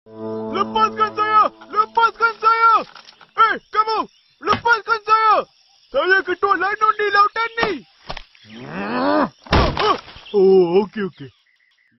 Genre: Nada dering lucu